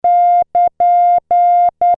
和文符号wabun
和文の文字をクリックすると和文符号が再生されます。